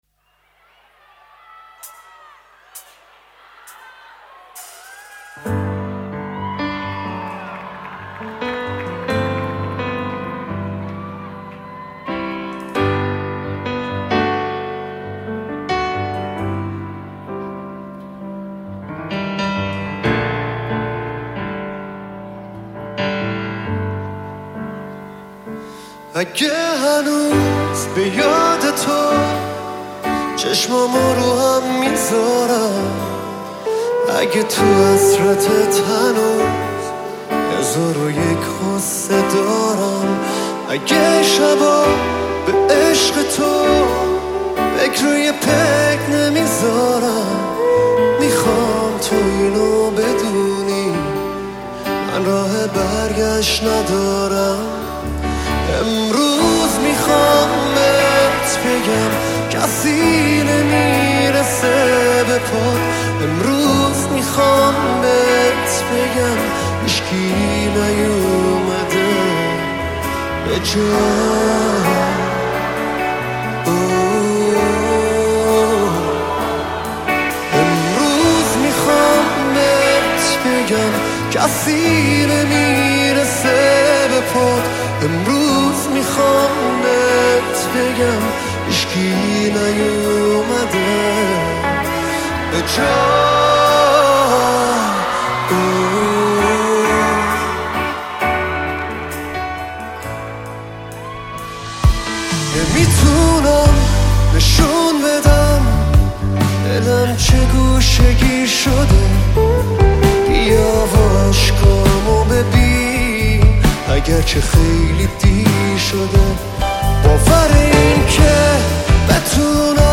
اجرای زنده
Live Version